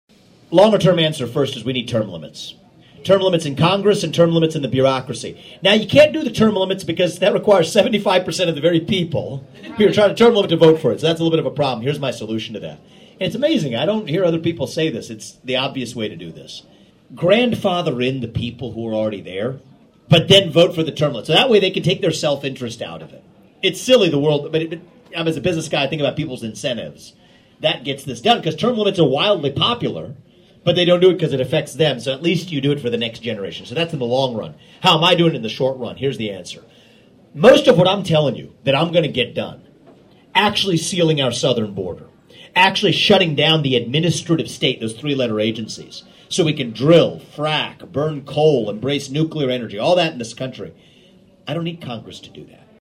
(Atlantic) U.S Presidential Candidate Vivek Ramaswamy, appearing at the Atlantic Pizza Ranch Thursday afternoon, spoke about the need for term limits.